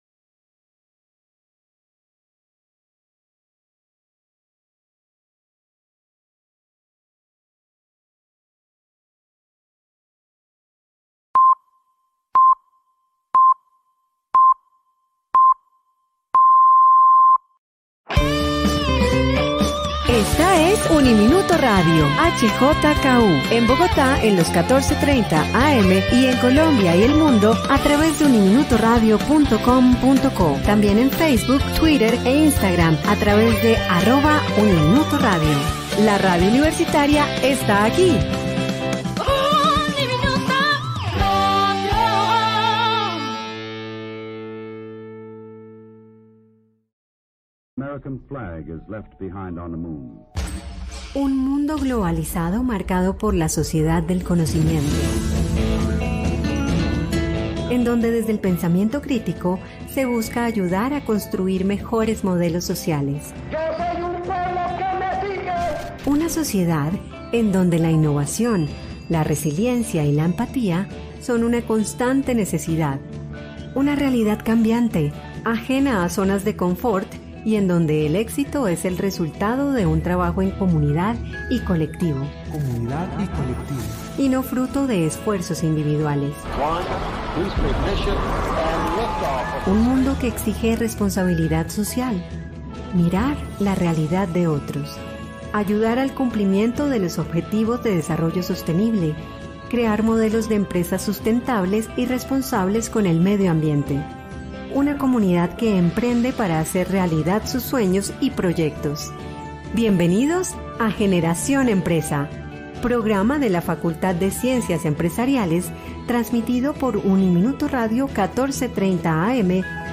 Entrevista al Contador GN Uniminuto Radio
entrevista-al-contador-gn-uniminuto-radio